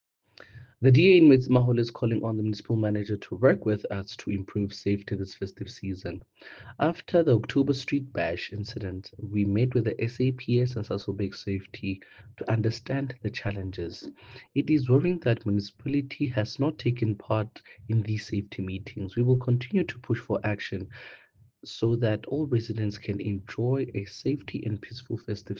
Afrikaans soundbites by Cllr Teboho Thulo and